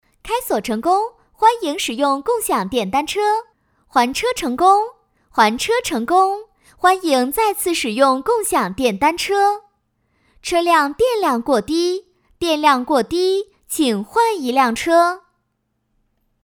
女-027号-列表页